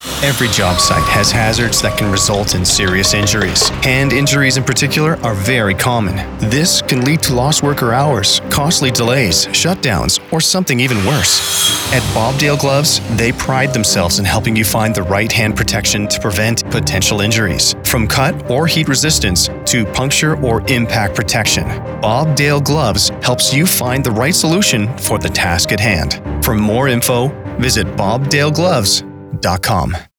BDG Audio Commercial
Bob Dale Gloves “Created For Trades” 30 second radio commercial, October 2024